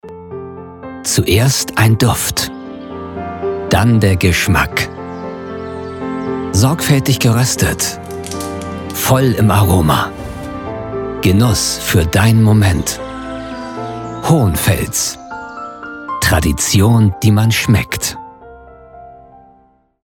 markant, plakativ
Mittel plus (35-65)
Norddeutsch
Eigene Sprecherkabine
Comment (Kommentar), Narrative, Off